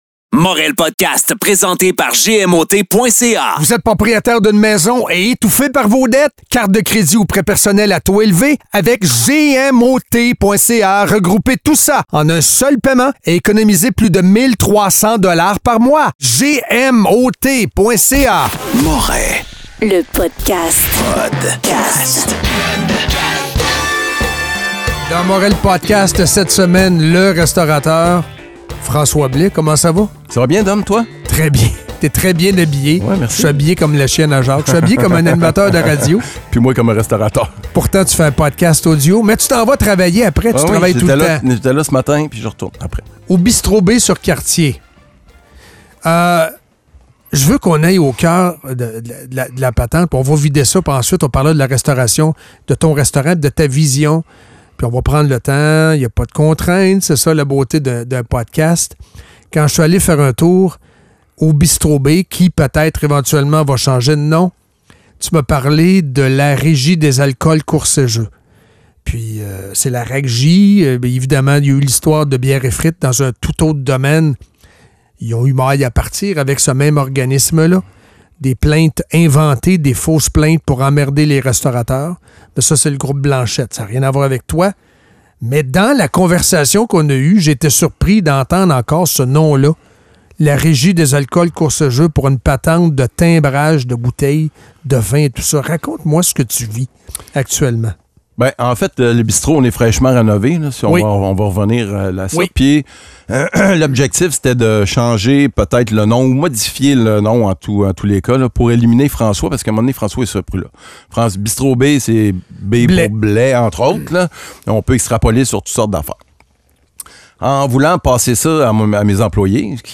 Tête à tête